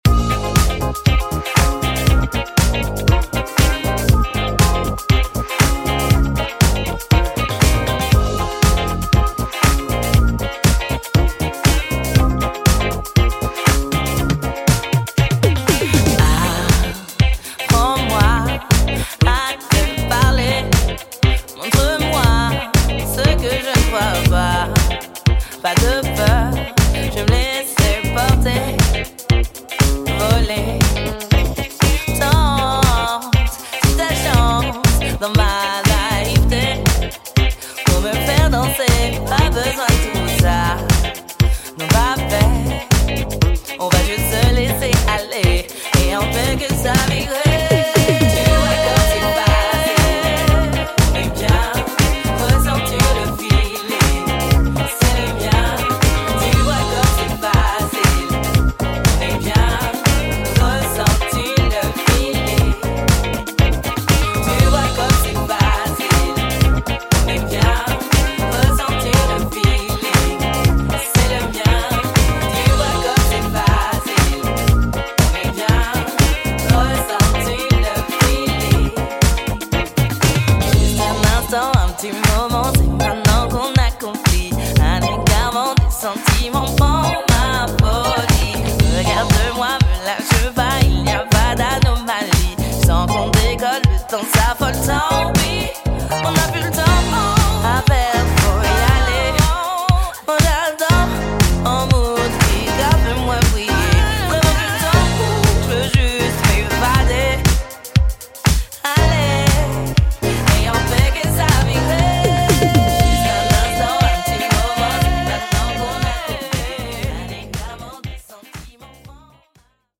marries live elements with modern club-ready production
soulful EP title track performance